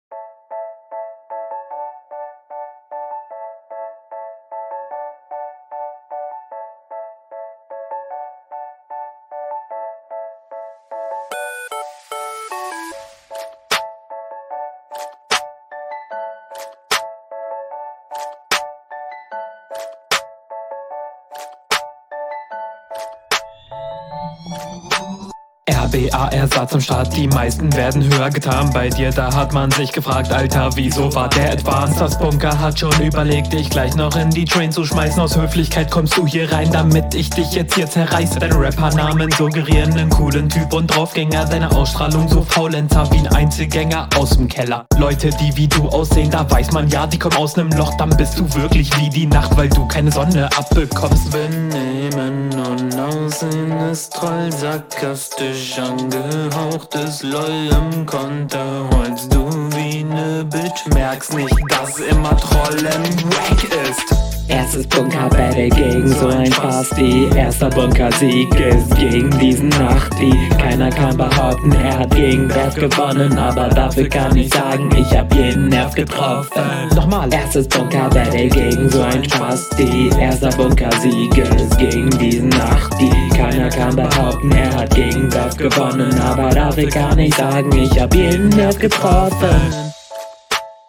Puh, also Sound ist schon einmal wirklich miserabel.
Dein Stimmeinsatz ist nicht nicht wirklich gut.